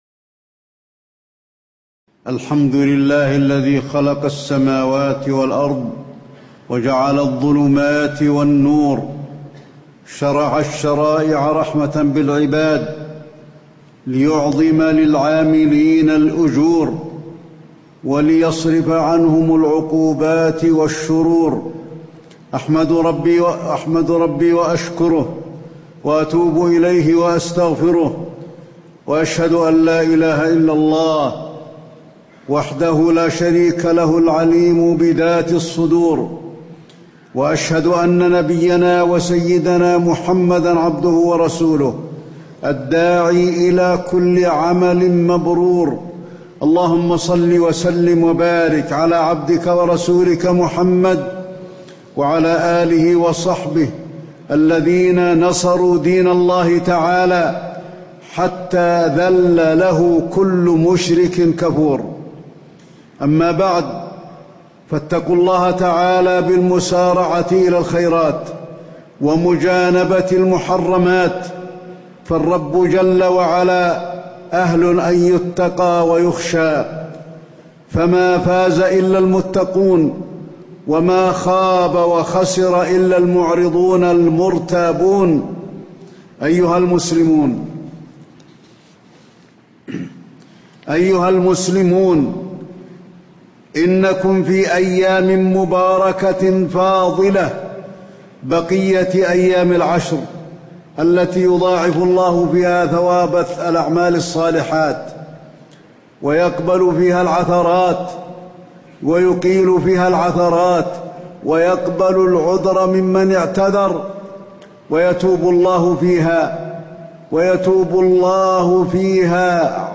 تاريخ النشر ٨ ذو الحجة ١٤٣٧ هـ المكان: المسجد النبوي الشيخ: فضيلة الشيخ د. علي بن عبدالرحمن الحذيفي فضيلة الشيخ د. علي بن عبدالرحمن الحذيفي أعمال أيام العشر من ذي الحجة